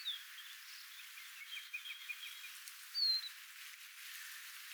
mika_laji_onko_kulorastas.mp3